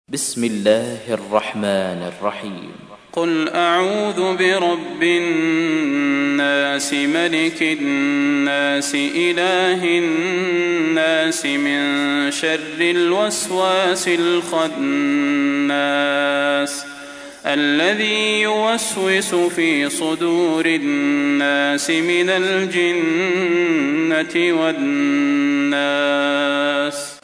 تحميل : 114. سورة الناس / القارئ صلاح البدير / القرآن الكريم / موقع يا حسين